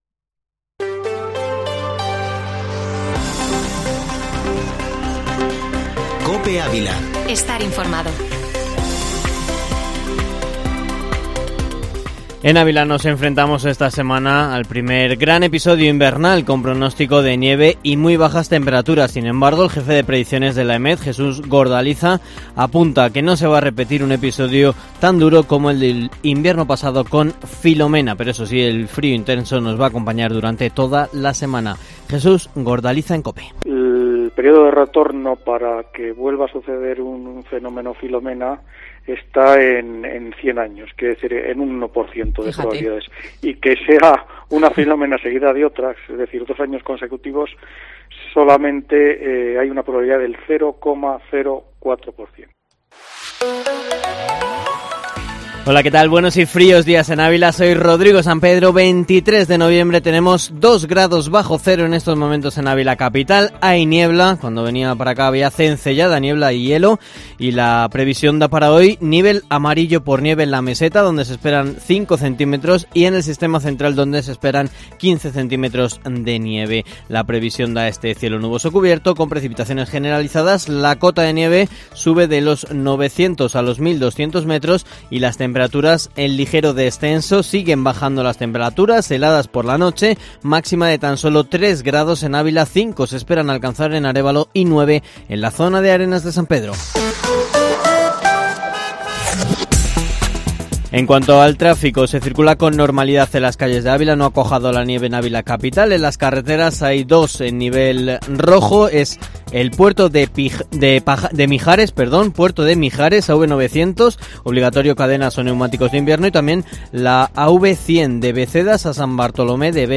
Informativo Matinal Herrera en COPE Ávila, información local y provincial